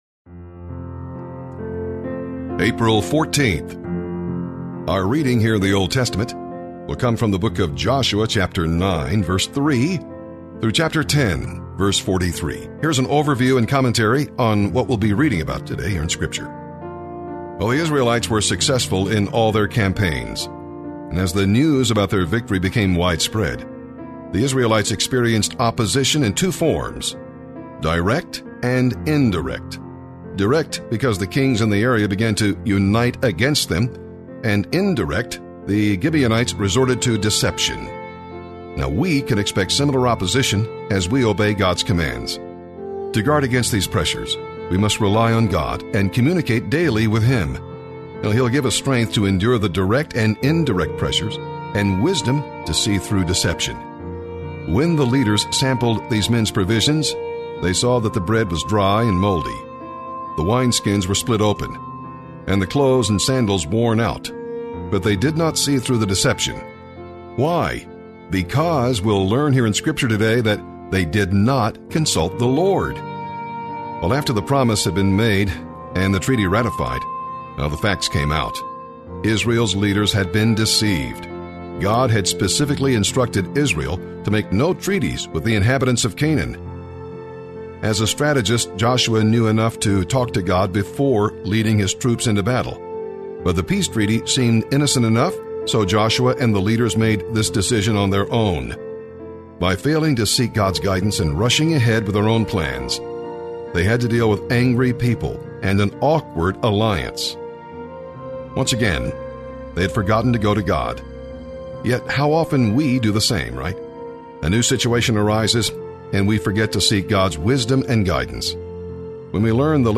April 14th Bible in a Year Readings